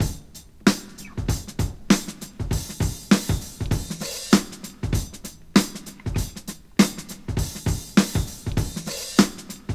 • 99 Bpm Breakbeat Sample D# Key.wav
Free drum groove - kick tuned to the D# note. Loudest frequency: 2601Hz
99-bpm-breakbeat-sample-d-sharp-key-Edr.wav